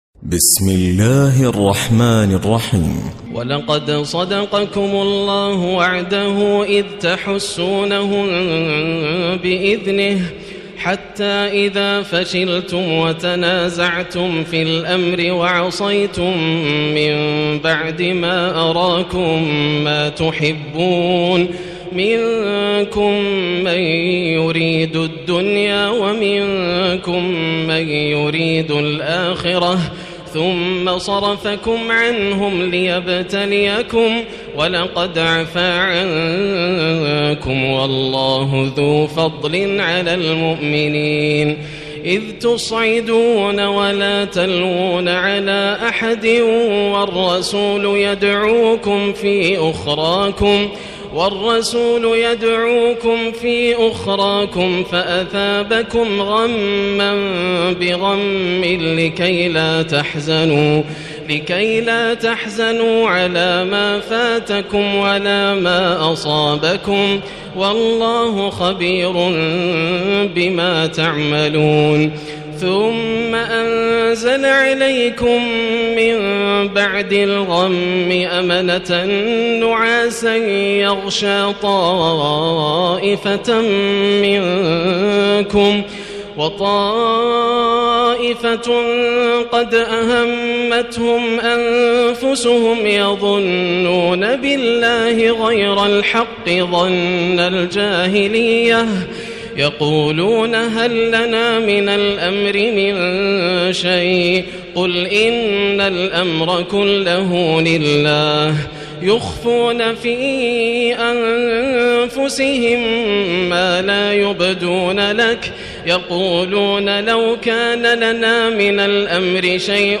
الليلة الرابعة كاملة - ماتيسر من سورة آل عمران 152 إلى سورة النساء 18 > الليالي الكاملة > رمضان 1437هـ > التراويح - تلاوات ياسر الدوسري